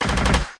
描述：采取了一系列的打击，并将其分层。踢鼓小鼓和高帽
标签： 游戏 一声枪响 军事 视频
声道立体声